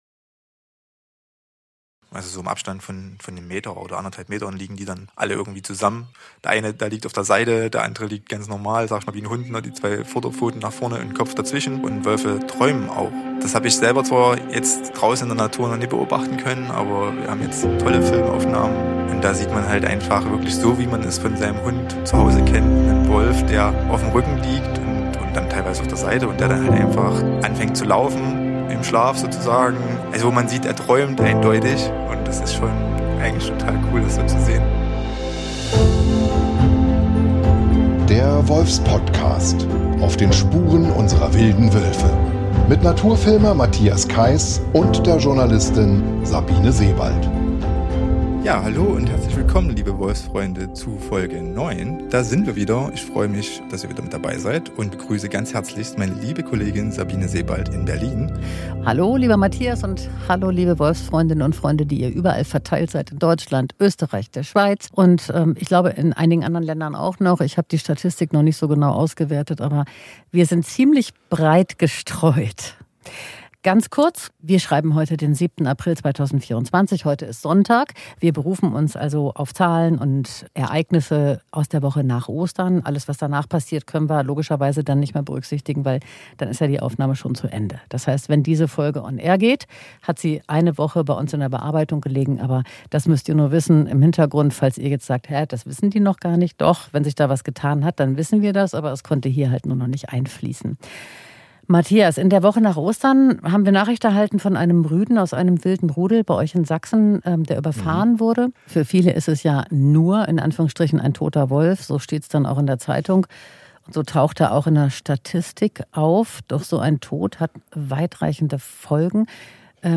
Die Podcaster diskutieren über die Jagd und ihre Folgen für die Natur.